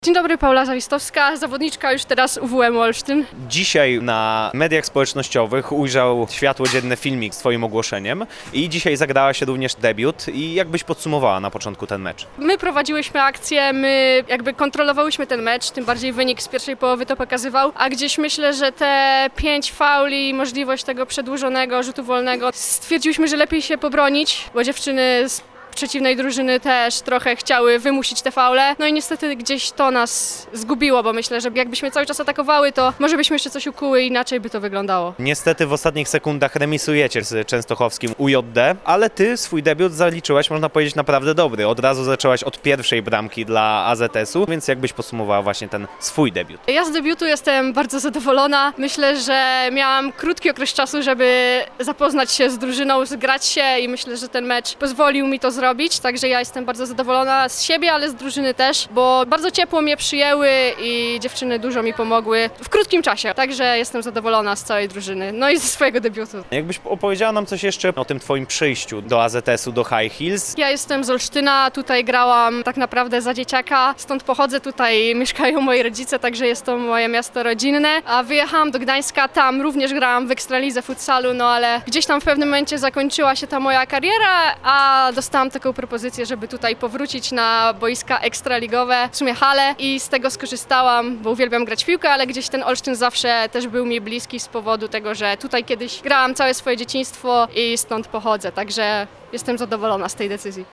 A jak ocenia swój debiut i dlaczego zdecydowała się dołączyć do AZS-u?